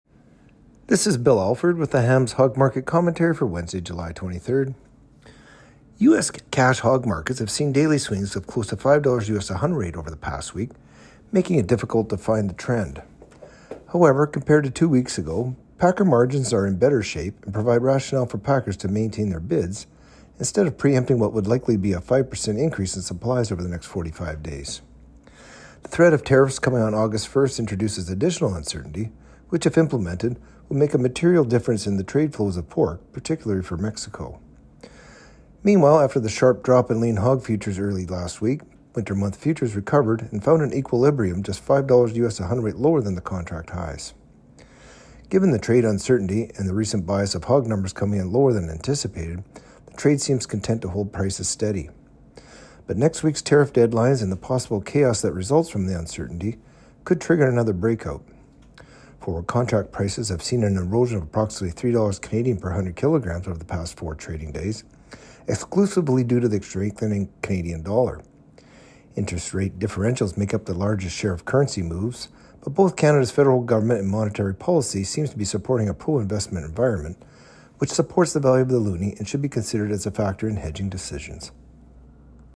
Hog-Market-Commentary-Jul.-23-25.mp3